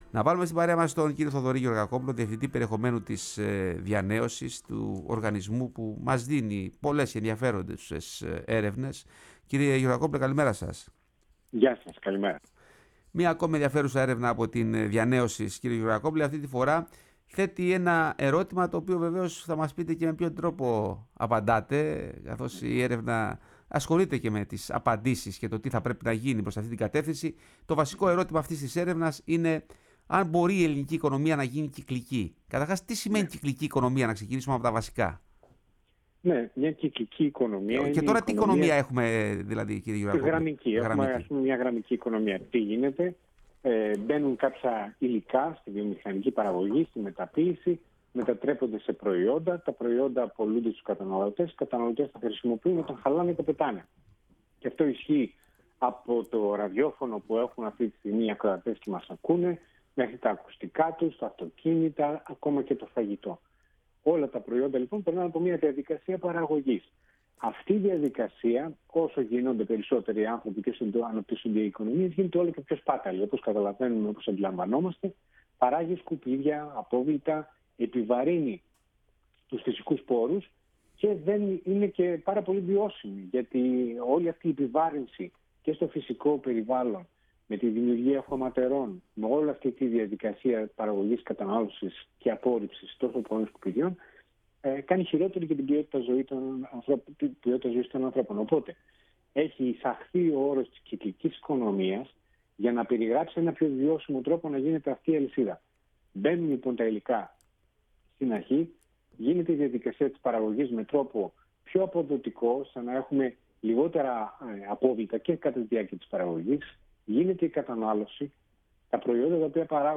Η ΦΩΝΗ ΤΗΣ ΕΛΛΑΔΑΣ Η Ελλαδα στον Κοσμο ΣΥΝΕΝΤΕΥΞΕΙΣ Συνεντεύξεις διανεοσις Κυκλικη οικονομια μελετη ΙΟΒΕ